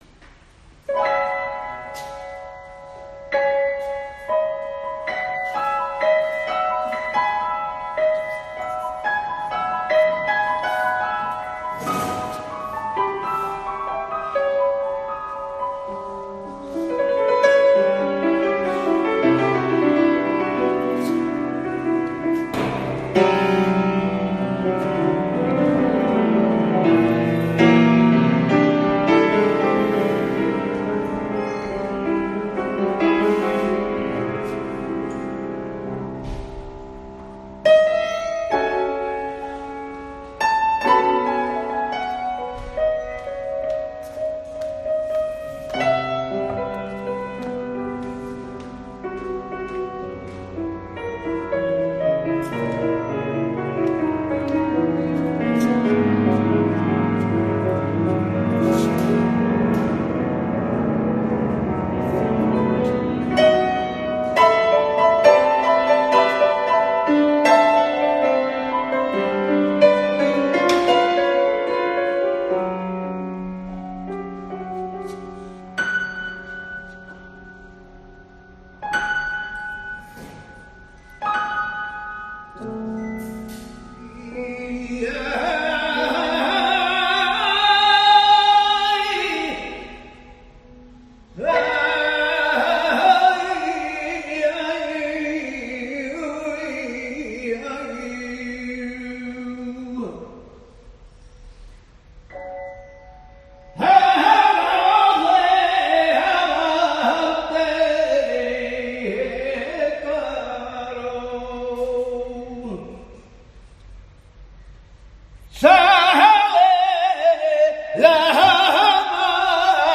In memoriam', interpreta al piano, junto al cante de El Pele, un fragmento de su obra sinfónica durante su presentación ante la presidenta de Diputación y el alcalde de Sanlúcar